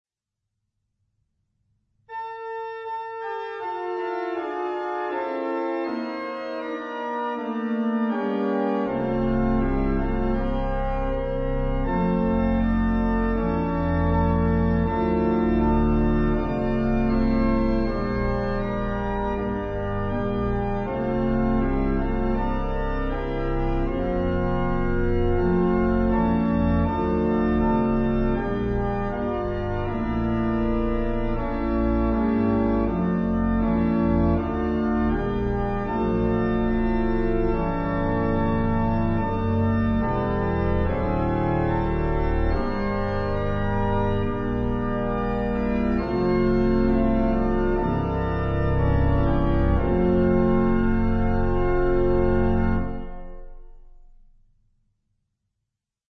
Christmas hymn
hymn harmonizations